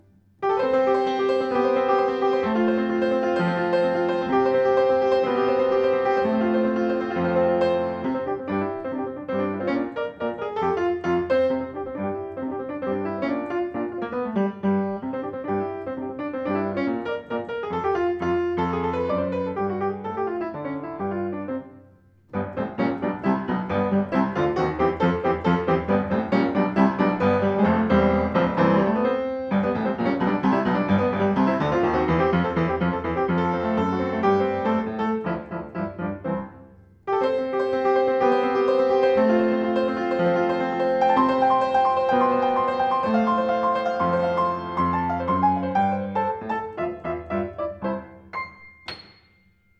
Klavier Yamaha U1 weiss
Klangbeispiele eines baugleichen Klavieres von uns: